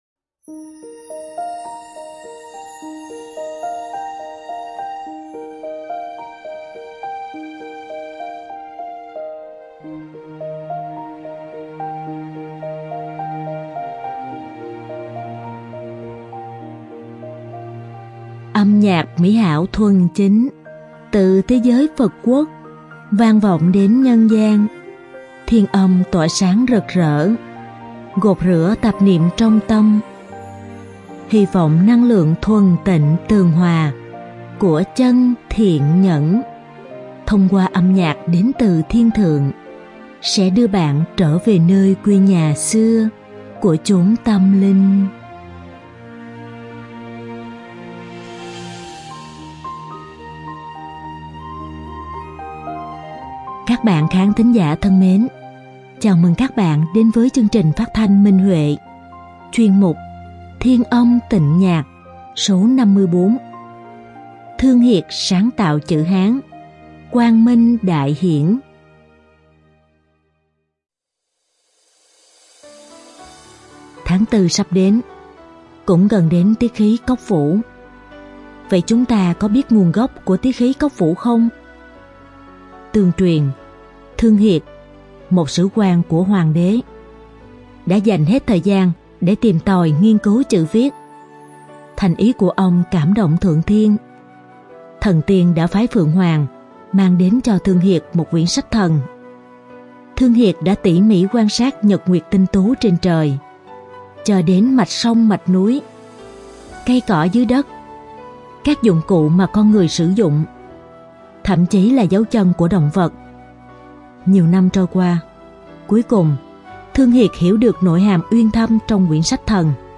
Đơn ca nữ
Đơn ca nam